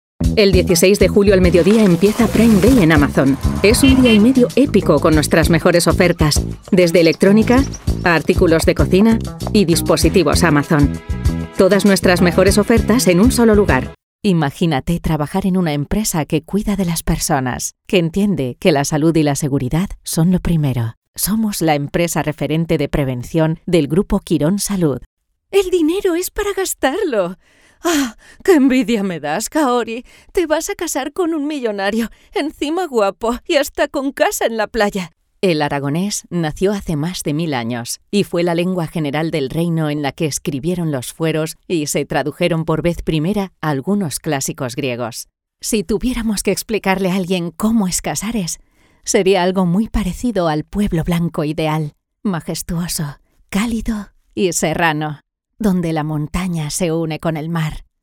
PERFIL VOZ: Rotunda, cálida, clara, carismática, enérgica, vibrante, entusiasta, sofisticada, sensual, versátil, convincente, épica, profunda, elegante.
Sprechprobe: Werbung (Muttersprache):
I have a castillian, native Spanish accent.